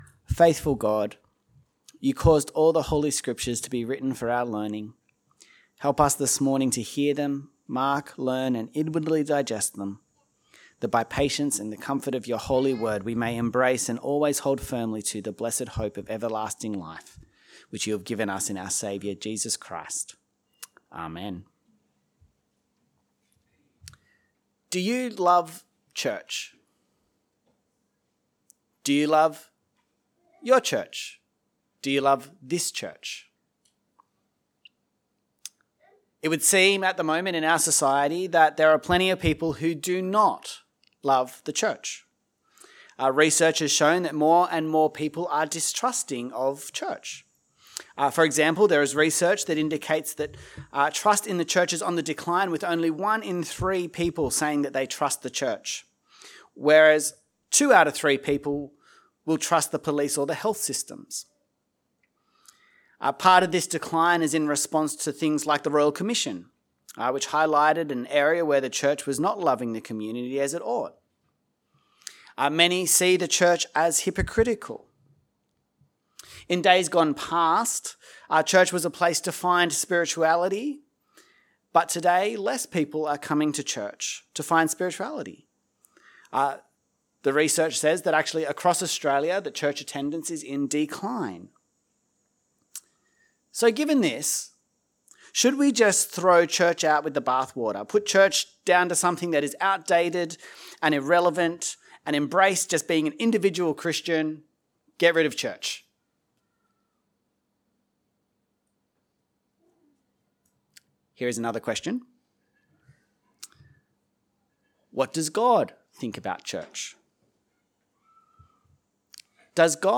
A message from the series "One Off Sermons."